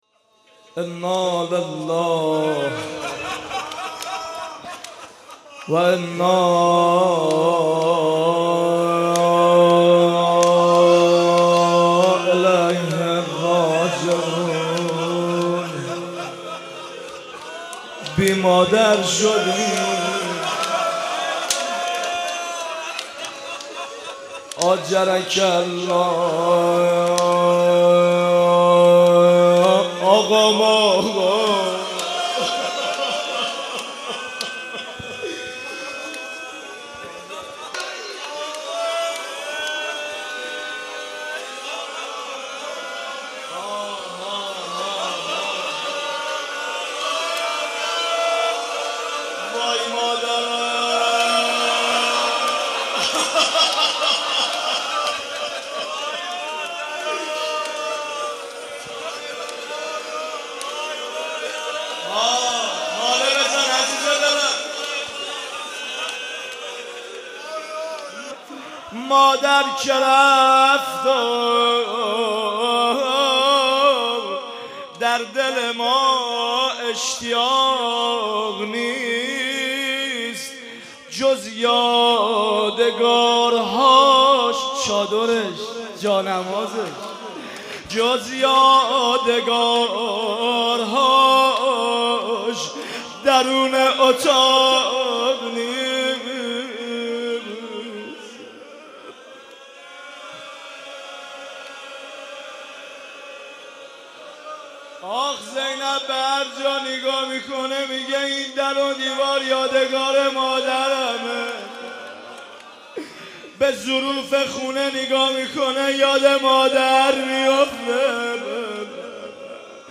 روضه فاطمیه ۹۰
fatemieh-roze1.mp3